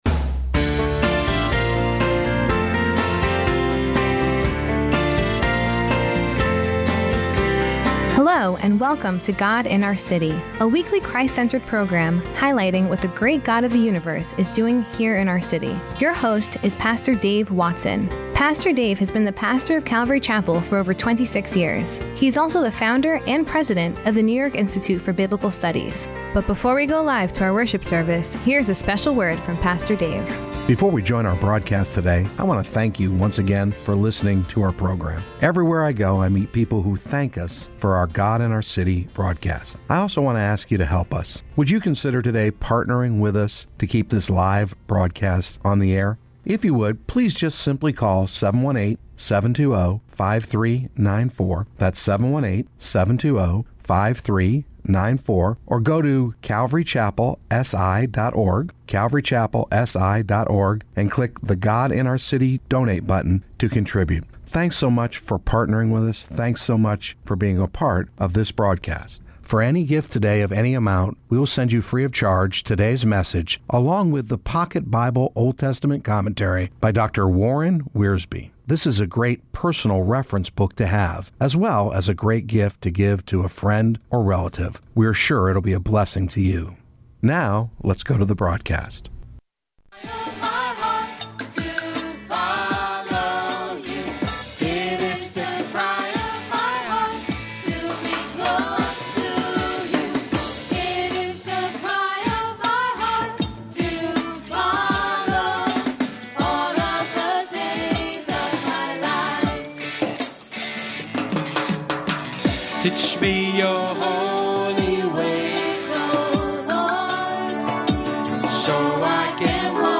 Podcast of this past Sunday’s “God in Our City” broadcast – “To Save a Generation”